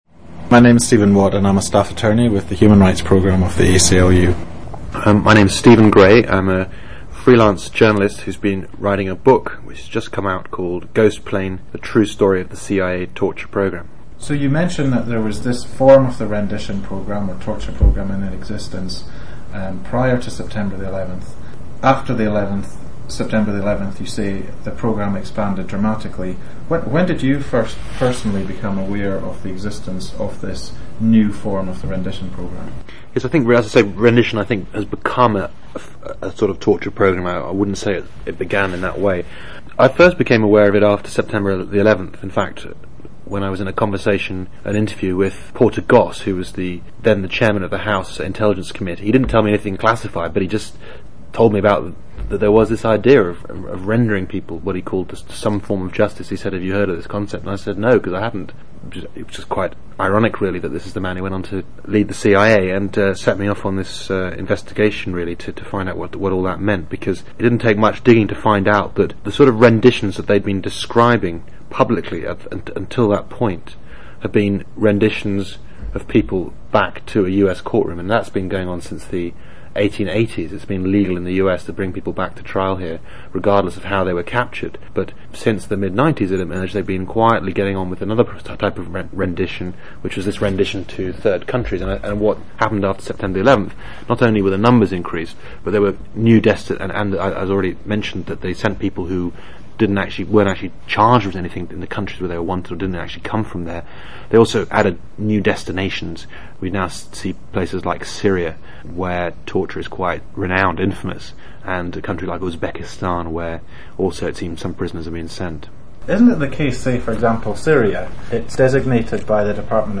A Conversation About the CIA Torture Program